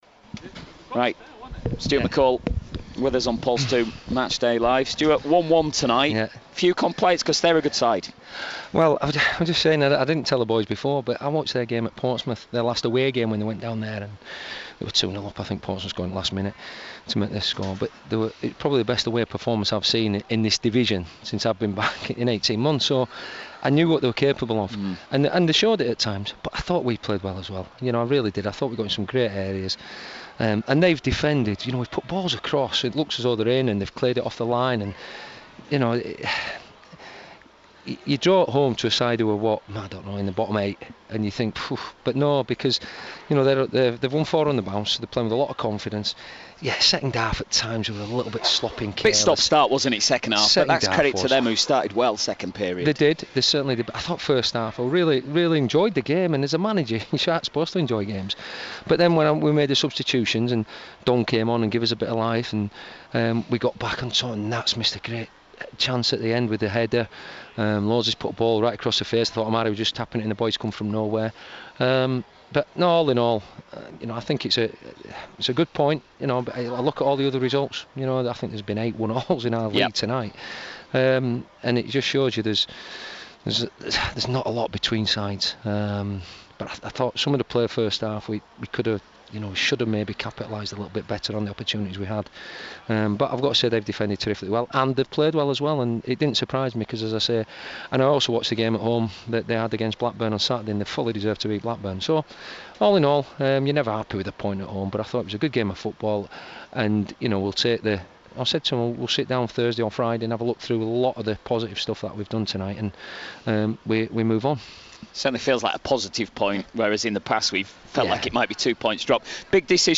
Stuart McCall Post Match Interview vs Oldham